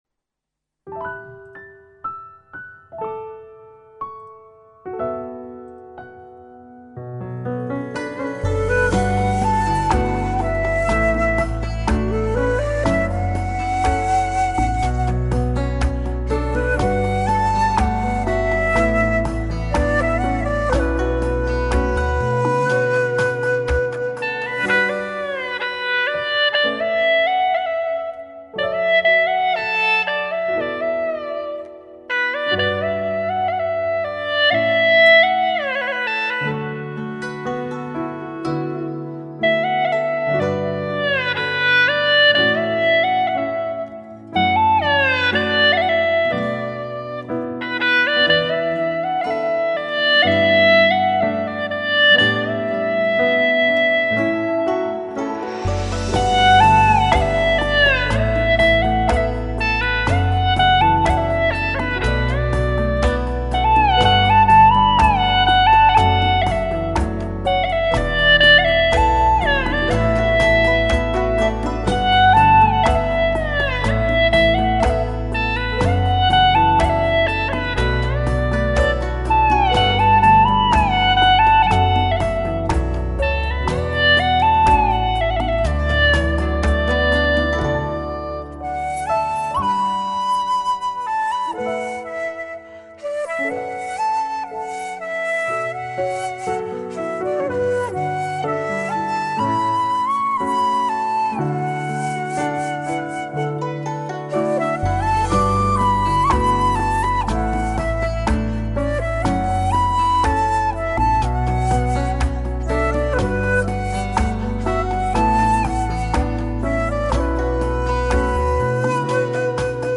调式 : D 曲类 : 古风
【大小D调】 我要评论